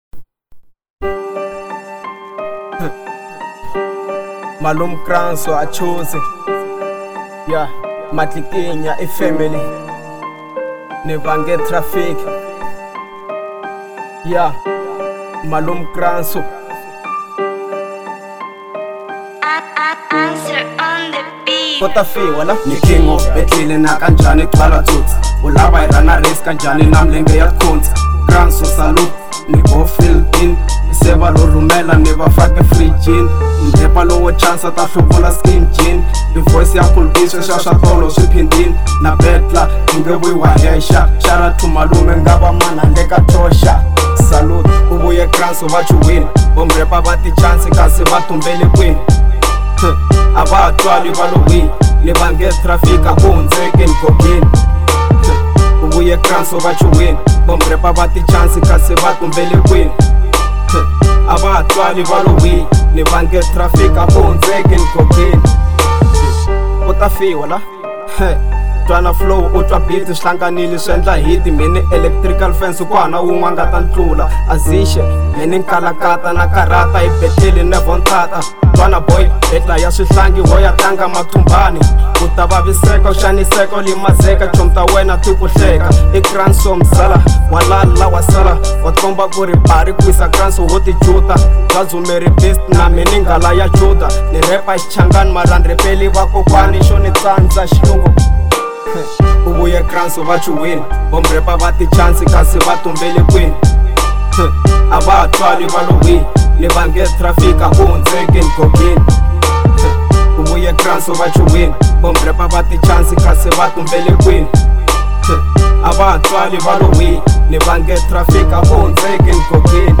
03:05 Genre : Hip Hop Size